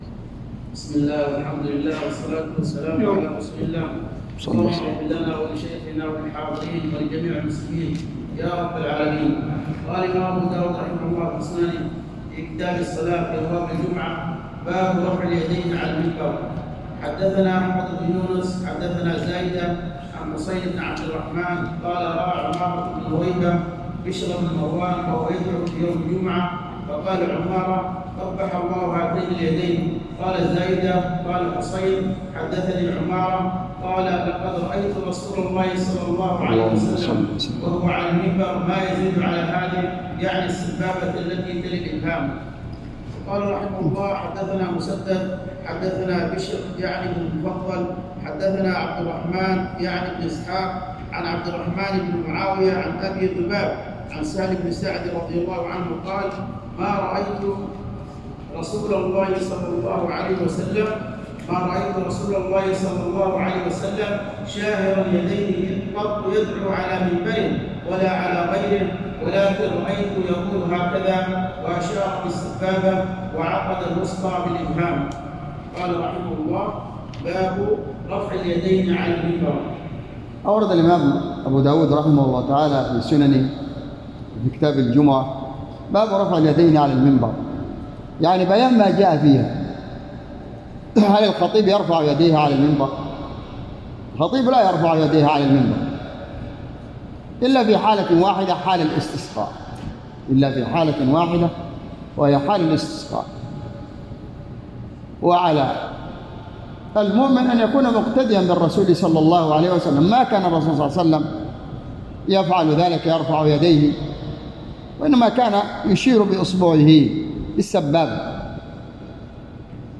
تسجيل لدروس شرح كتاب الجمعة - سنن أبي داود  _ بجامع الدرسي بصبيا